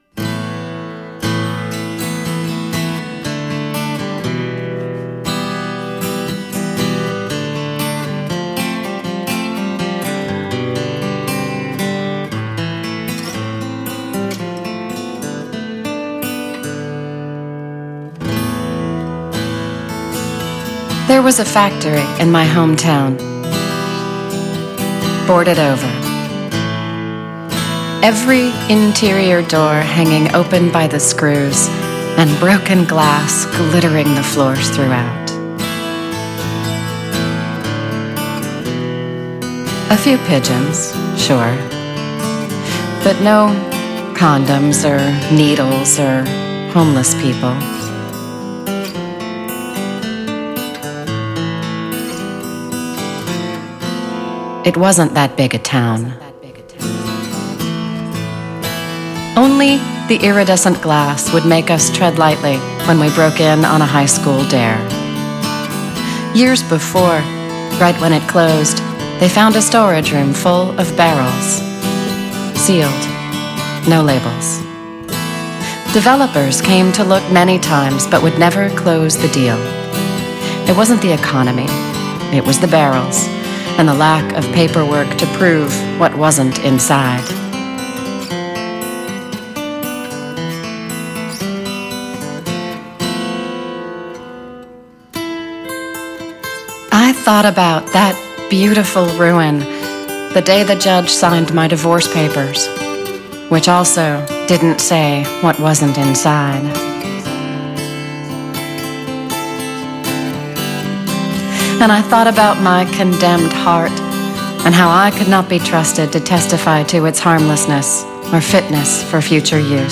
With original music